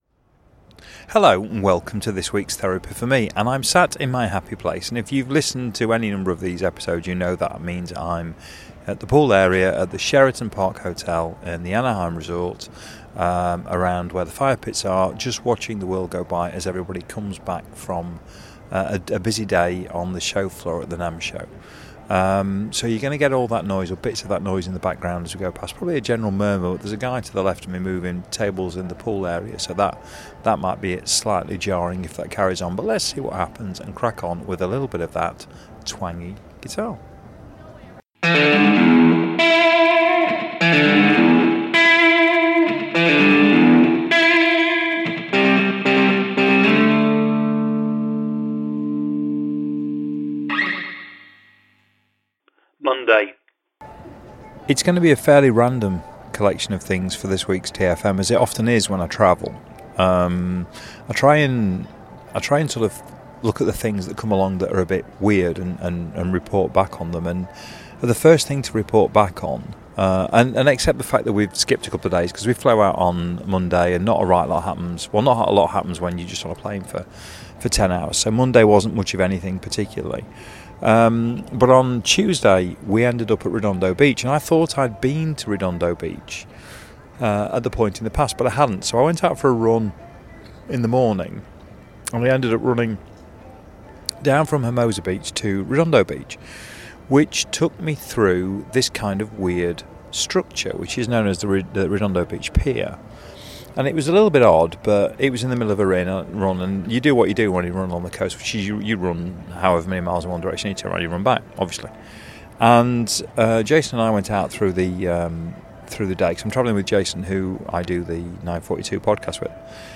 It's remains loose in style, fluid in terms of content and raw - it's a one take, press record and see what happens, affair.